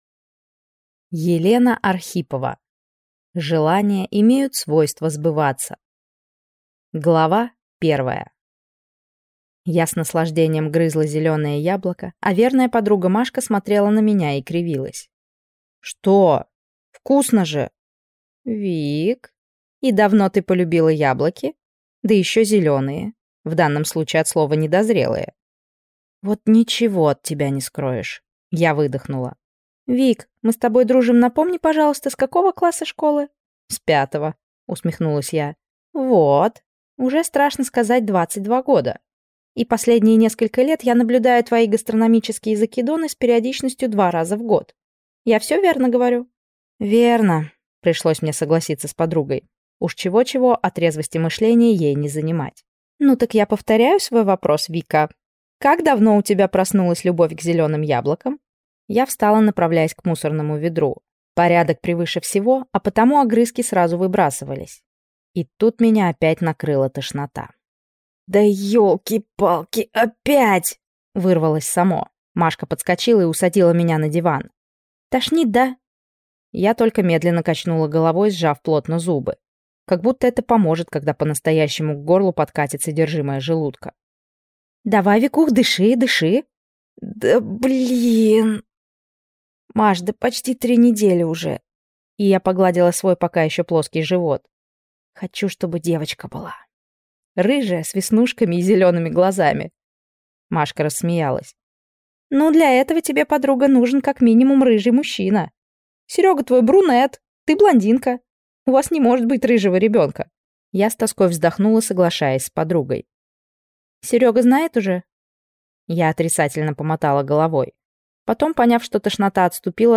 Аудиокнига Желания имеют свойства сбываться | Библиотека аудиокниг